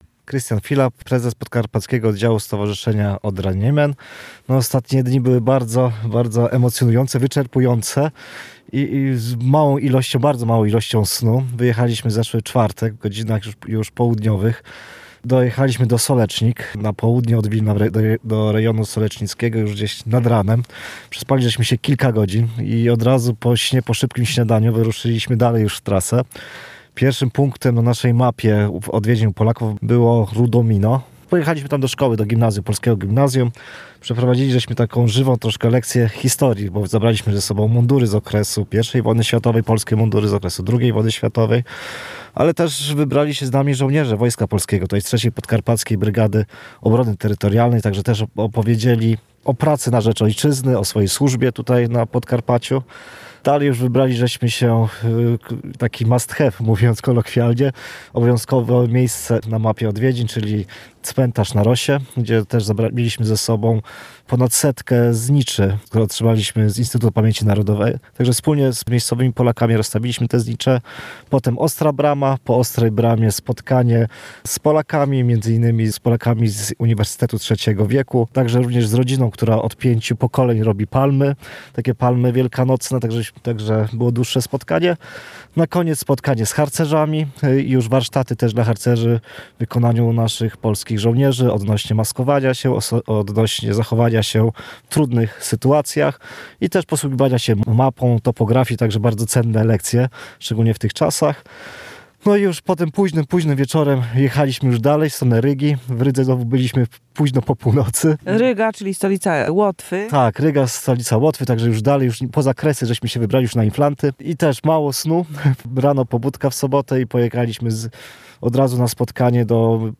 Relacje reporterskie • Żywność zebrana podczas przedświątecznej akcji „Rodacy Bohaterom”, zorganizowanej przez podkarpacki oddział Stowarzyszenia Odra-Niemen, trafiła do Polaków mieszkających na Litwie i Łotwie.